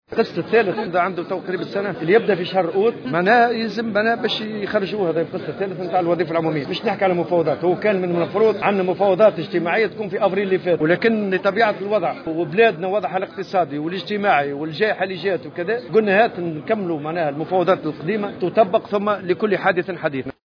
وأكد الطبوبي في تصريح اعلامي على هامش افتتاحه اشغال المؤتمر العادي للاتحاد الجهوي للشغل بسوسة ان اتحاد الشغل سيتفاعل مع تركيبة الحكومة الجديدة التي من المنتظر ان يشكلها هشام المشيشي، على ضوء ما ستقدمه من برامج ومشاريع لمجابهة التحديات المطروحة ولاسيما معالجة الوضع الاقتصادي والاجتماعي الصعب ومواصلة التوقي الصحي من جائحة كورونا.